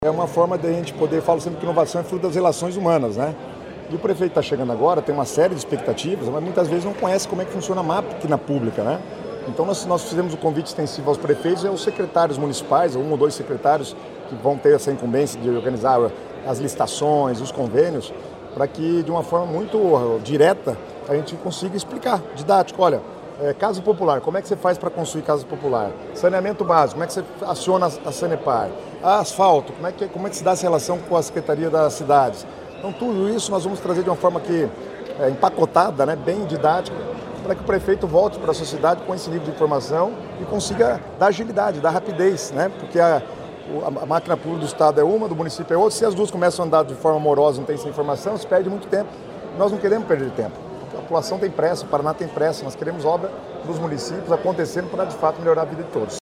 Sonora do secretário do Planejamento, Guto Silva, sobre o Paraná Mais Cidades